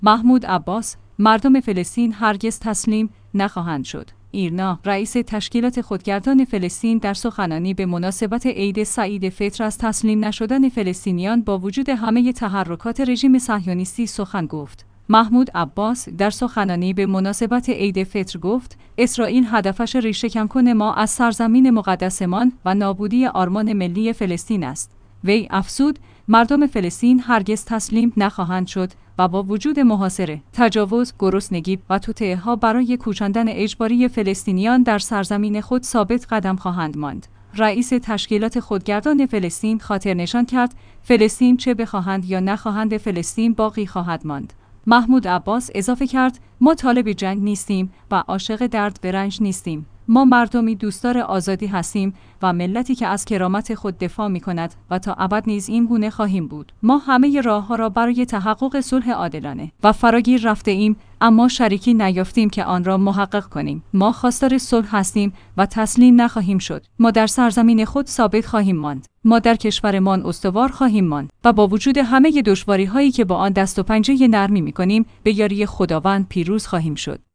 ایرنا/ رئیس تشکیلات خودگردان فلسطین در سخنانی به مناسبت عید سعید فطر از تسلیم نشدن فلسطینیان با وجود همه تحرکات رژیم صهیونیستی سخن گفت. «محمود عباس» در سخنانی به مناسبت عید فطر گفت: اسرائیل هدفش ریشه کن کردن ما از سرزمین مقدس مان و نابودی آرمان ملی فلسطین است.